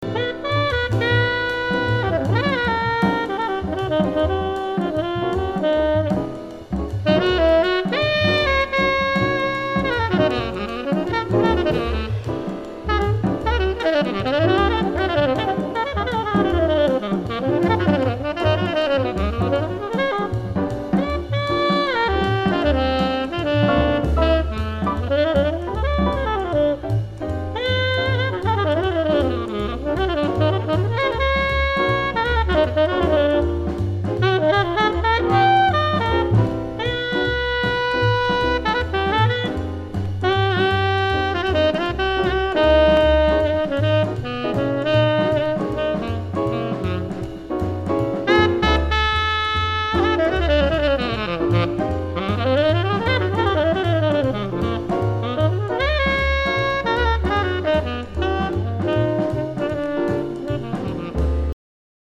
jazz
soprano saxophone
modal jazz